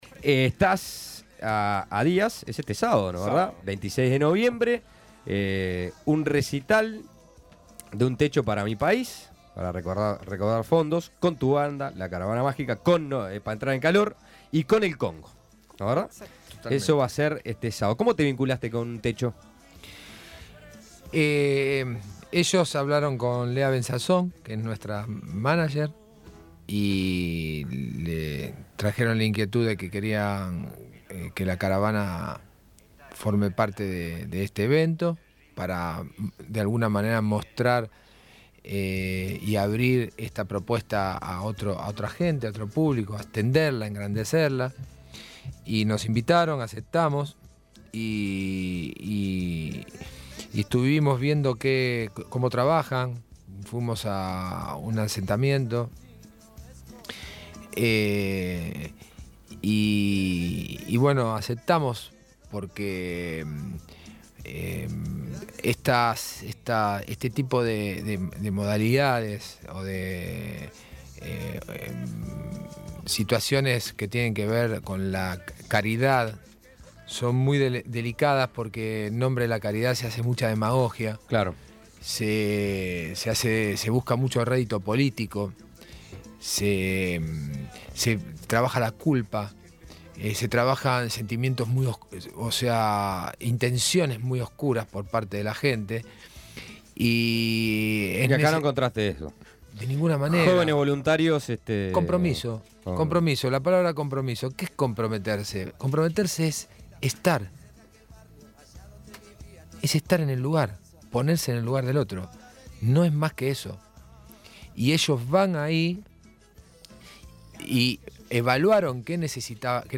El artista encargado de cerrar la velada será el argentino Gustavo Cordera, el ex líder de Bersuit Vergarabat, quien estará presentando un espectáculo con las canciones de su segundo disco en su etapa solista, "La Caravana Mágica". En la tarde del jueves el artista visitó el estudio de El Espectador y dialogó con Suena Tremendo.